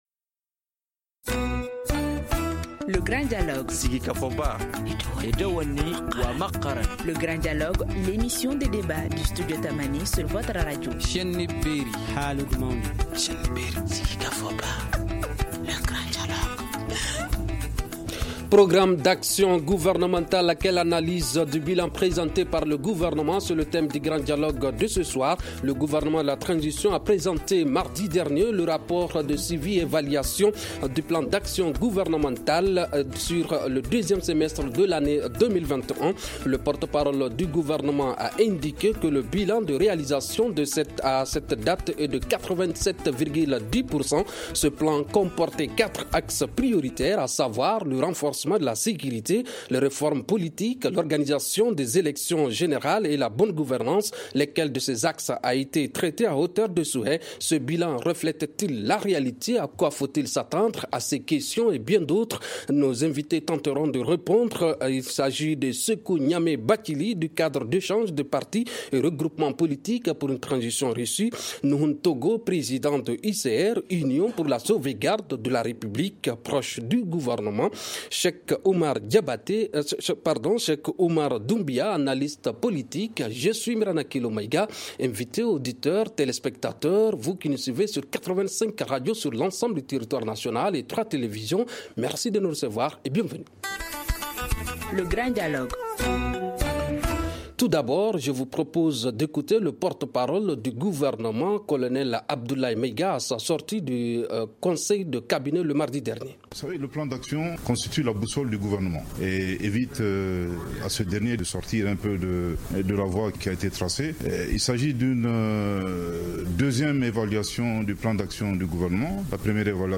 analyste politique